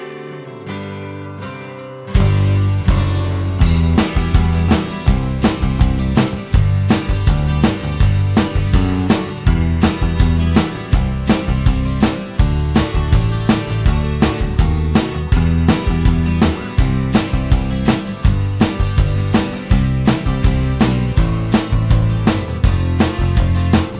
no Backing Vocals Soft Rock 4:08 Buy £1.50